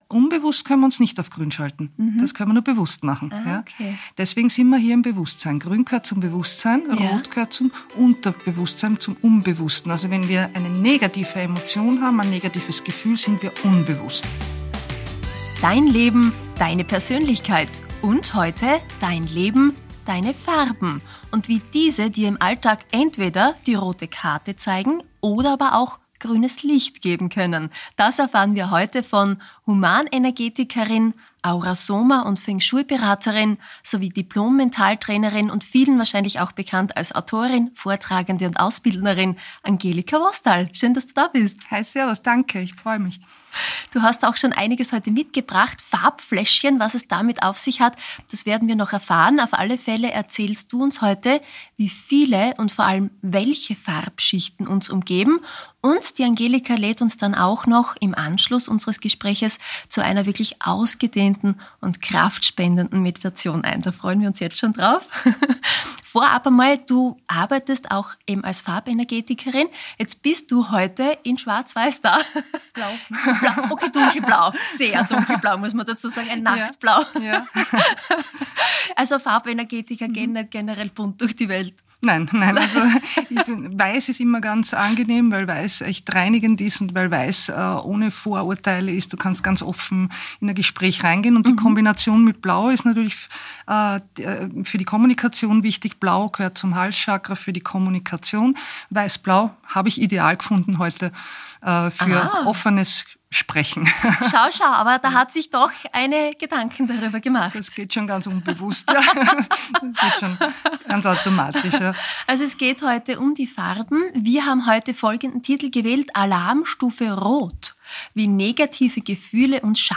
Am Ende dieser Folge gibt es eine Meditation zum Mitmachen!